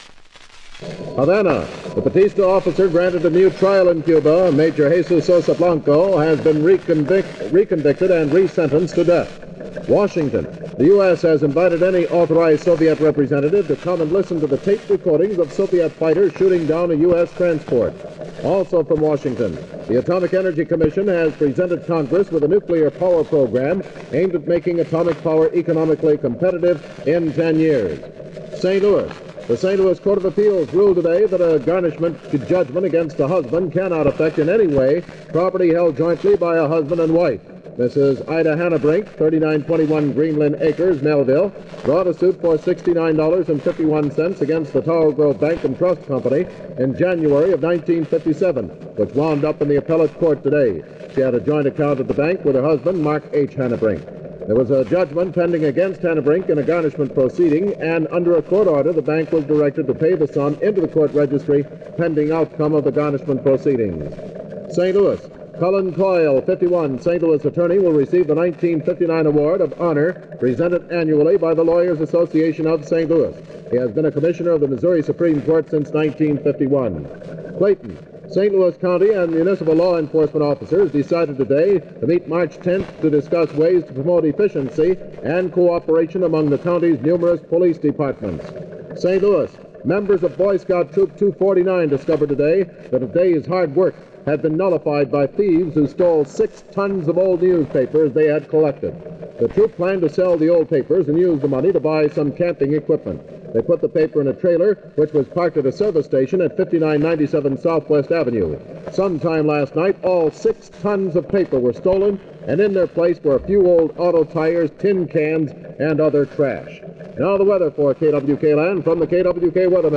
KWK News aircheck · St. Louis Media History Archive
Original Format aircheck